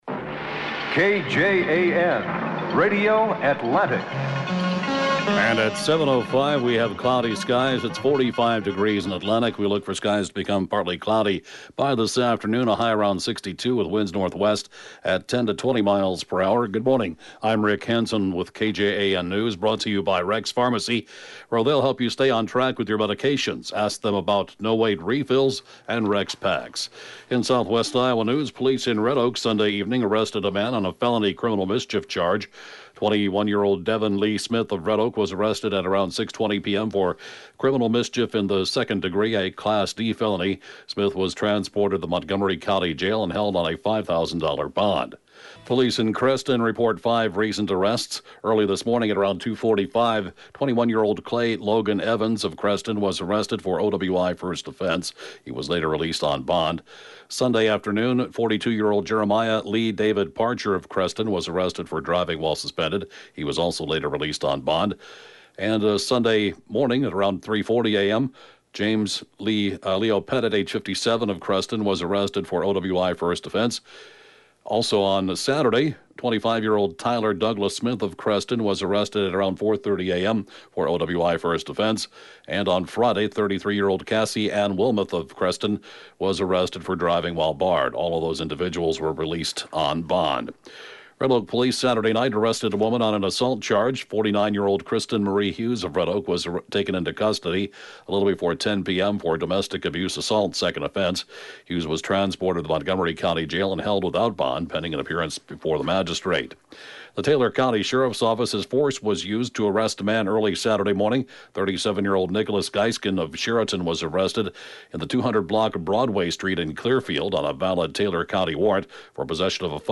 (Podcast) KJAN 8-a.m. News, 1/13/2018